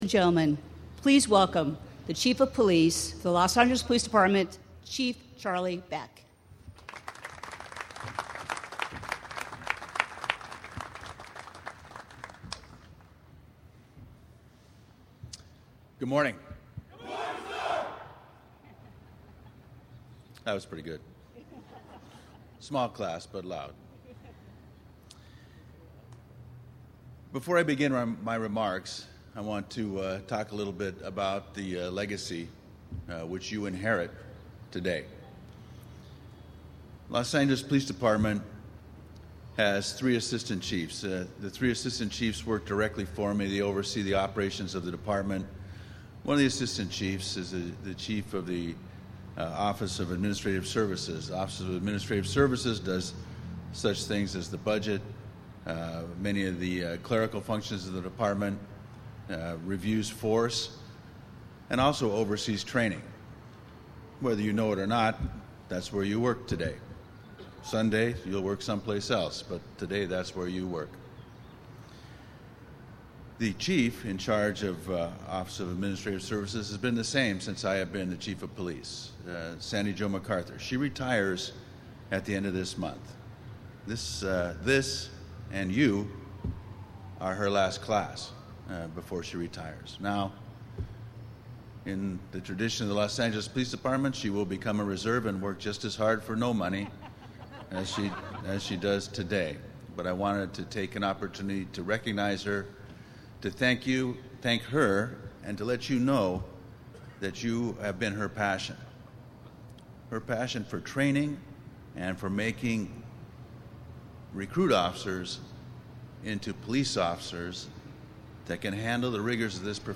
Download COP Grad Speech 3-20-15
March 20, 2025 – A Los Angeles Police Academy graduation ceremony was held this morning at the LAPD Administration Building plaza.
In full uniform, the recruits proudly appeared before Chief Charlie Beck and command staff, City officials and a large crowd of spectators.
cop-grad-speech-3-20-15.mp3